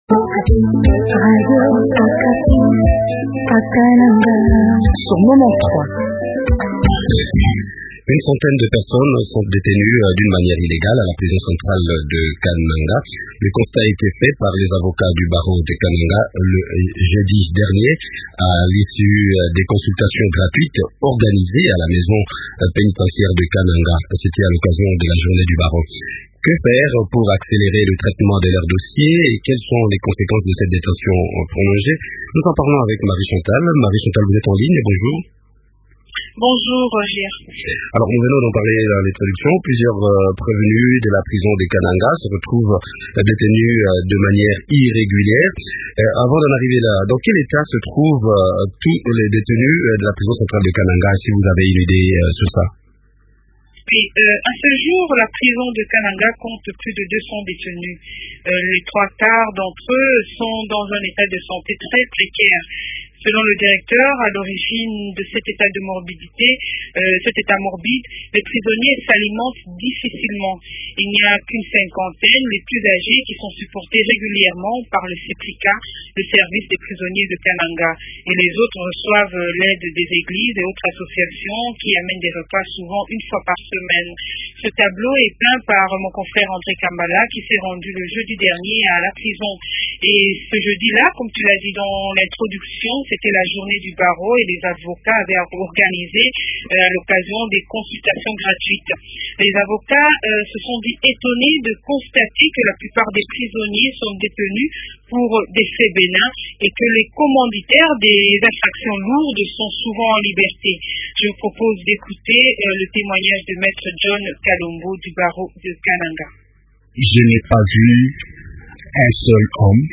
font le point avec Me Serge Bokele, ministre provincial de la justice.